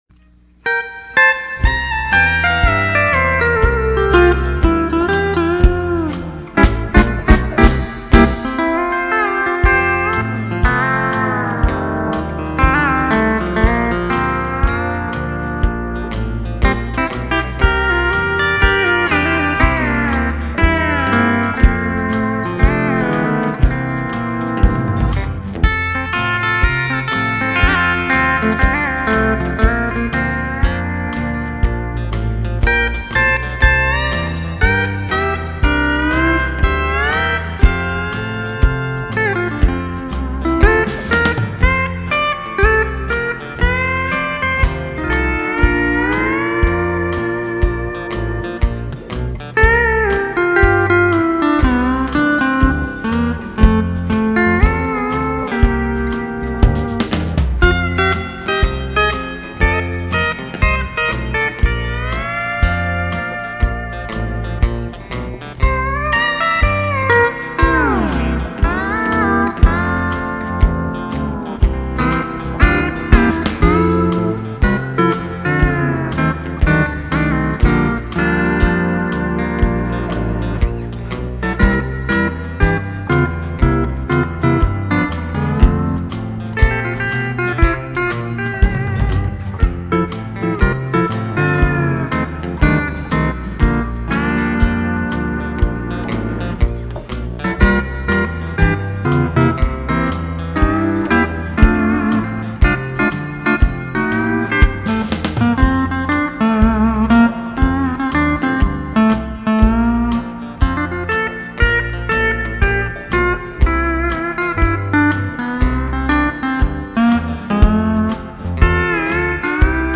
E9/C6th
Key Of F